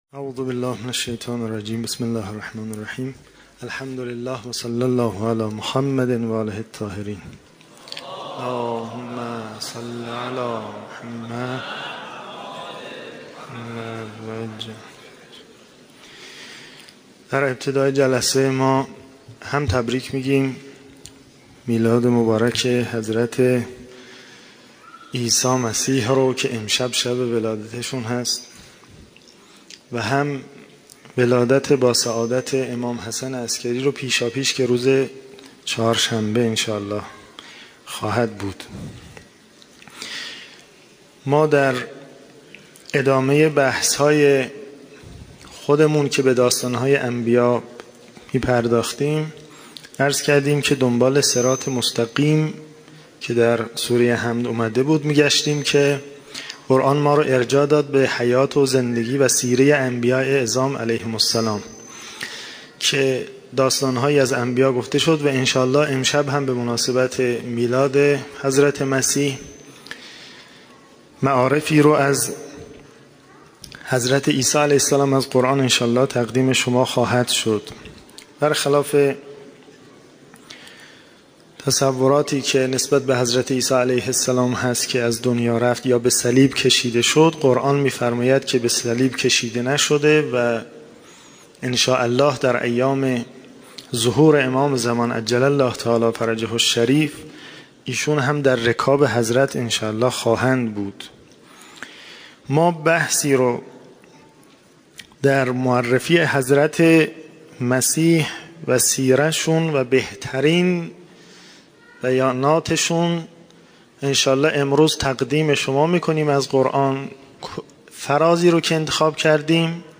همچنین آیات استناد شده در خلال سخنرانی، توسط یکی از قاریان ممتاز دانشگاه به صورت ترتیل قرائت می‌شود.